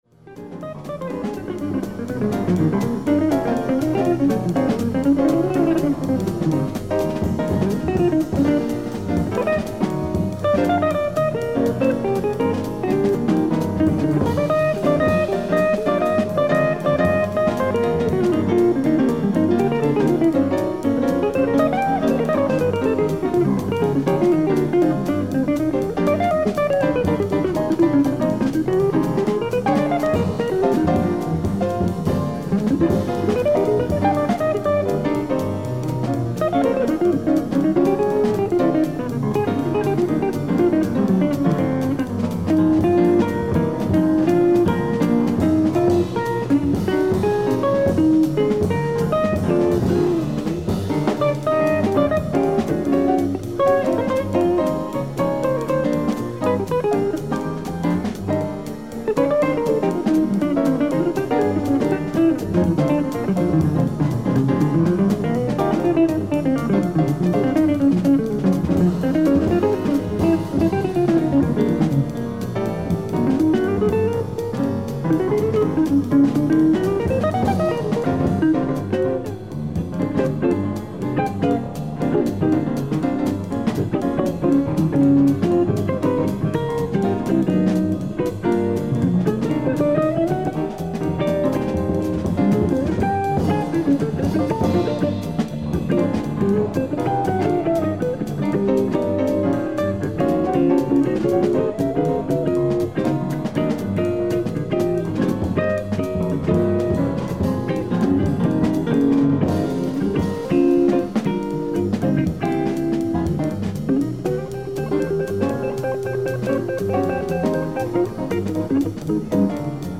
ライブ・アット・カフェカリブ、プレインフィールド、ニュージャージー 04/29/1973
音質もサンプルをお聴き頂ければお判りの通り問題の無いサウンドボード音源です。
※試聴用に実際より音質を落としています。